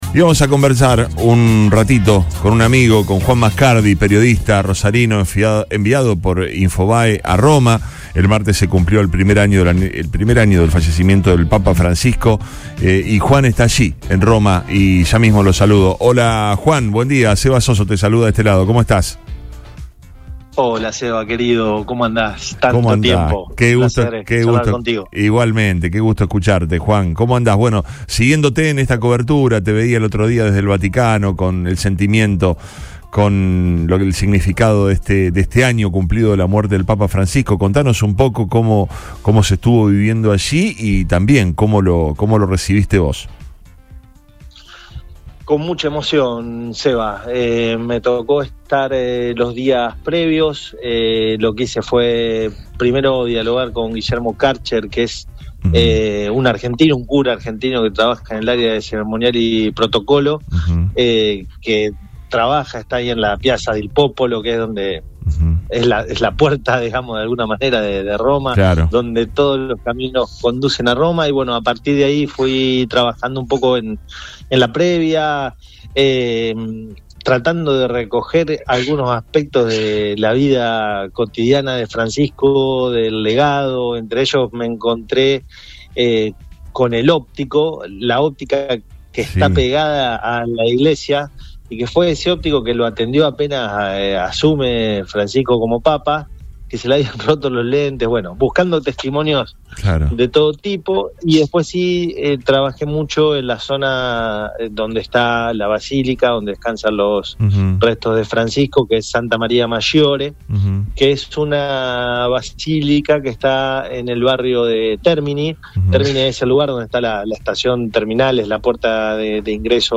Desde Roma, un periodista argentino revela cómo se vivió el aniversario por la muerte de Francisco